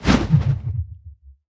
swish.wav